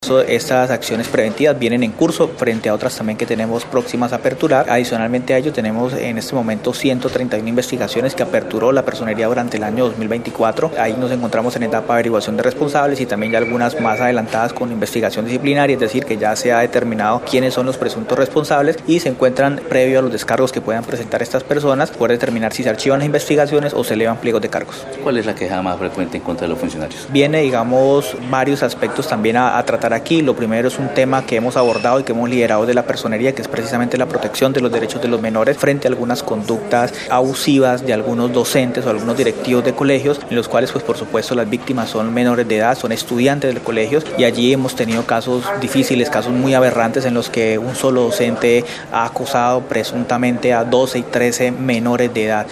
Jorge Luis Lara Andrade, personero del municipio de Florencia, explicó que su despacho investiga denuncias por verdaderos ´casos aberrantes´ donde un solo docente presuntamente ha acosado sexualmente hasta 13 menores de edad.